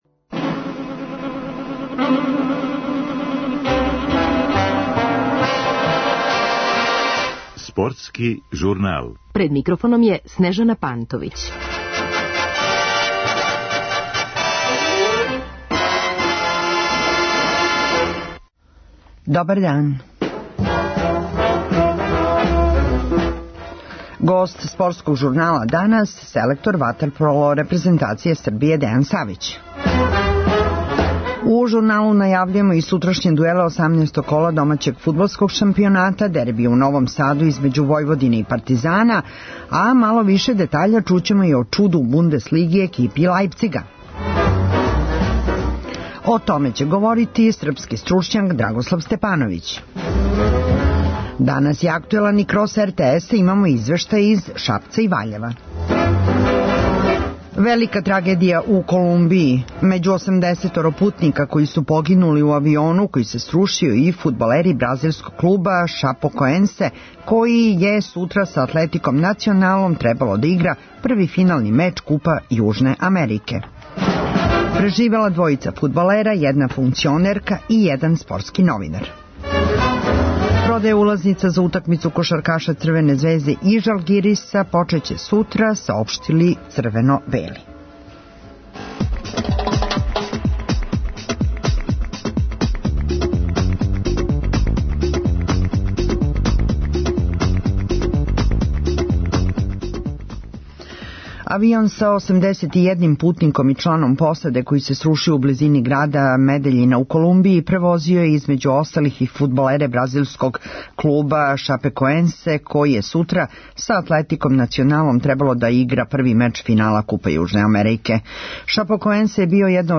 Гост емисије је селектор ватерполо репрезентације Србије Дејан Савић, који је од светског удружења ватерполо тренера добио ласкаво признање за најбољег стручњака у 2016-тој години.
У Спортском журналу најављујемо и сутрашње дуеле домаћег фудбалског шампионата, а мало више детаља чућемо и о чуду у немачком првенству. О томе говори прослављени српски стручњак Драгослав Степановић.